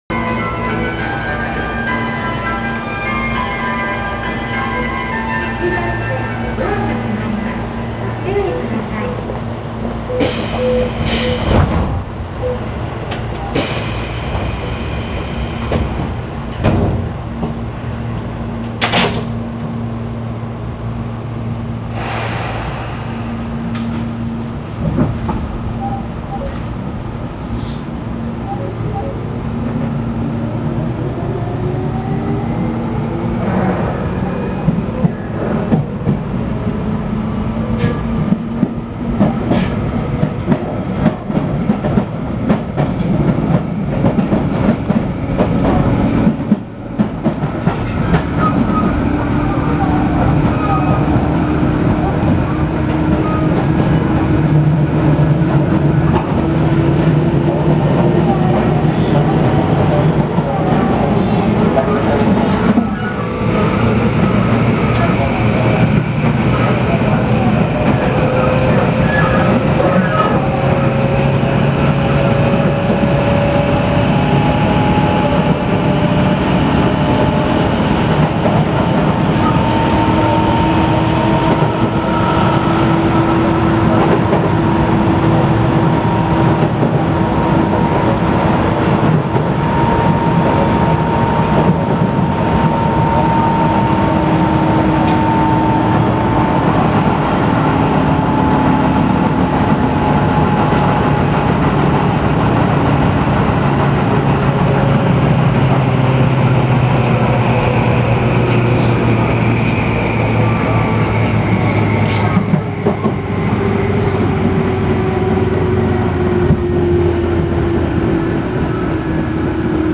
〜車両の音〜
・10030系走行音
【野田線】船橋→新船橋（2分30秒：815KB）
界磁チョッパを採用しており、10000系と同じ走行音になります。8000系と似ているものの、やや重厚な音になっている印象。